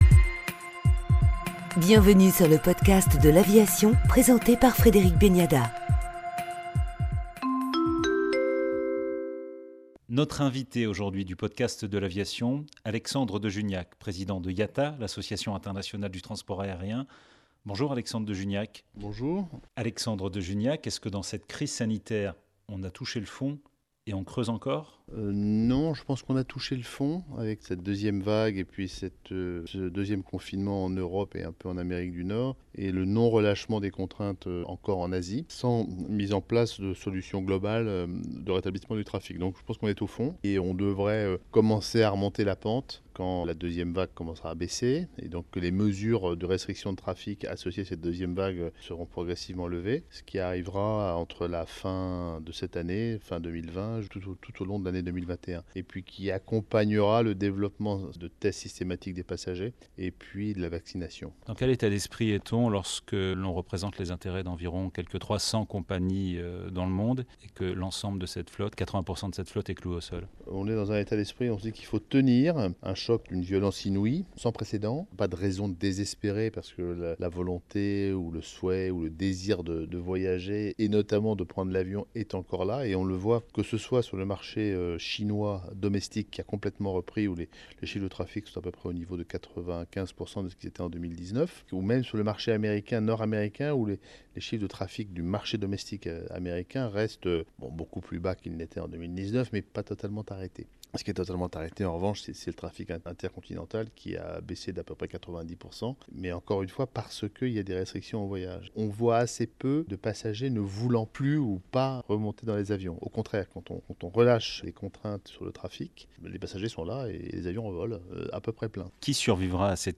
entretien-avec-alexandre-de-juniac-directeur-general-de-l-iata.mp3